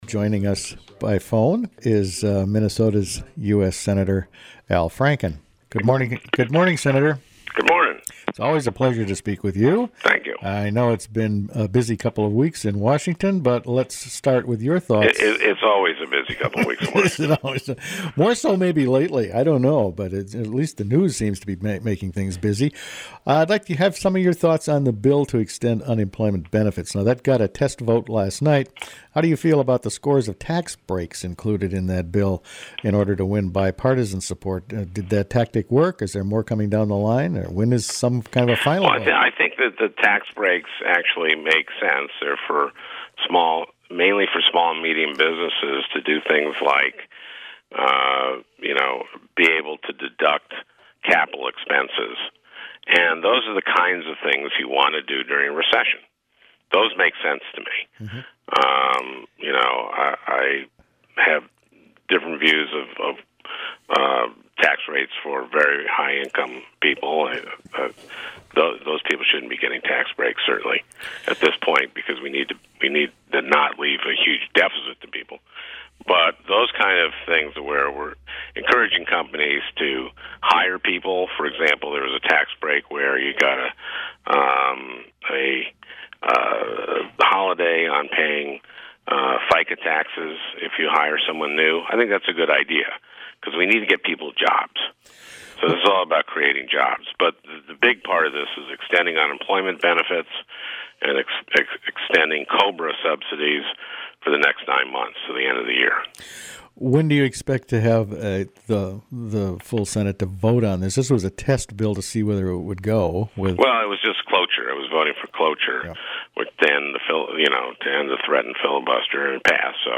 Attachment Size Franken030910Mixdown.mp3 19.23 MB During Wednesday morning’s “Daybreak” program Minnesota’s US Senator Al Franken weighed in on the extension of unemployment benefits and health care reform legislation, both top issues on the Senate’s agenda. Franken also addressed what he sees as problems with the No Child Left Behind program in some northern Minnesota Native American schools.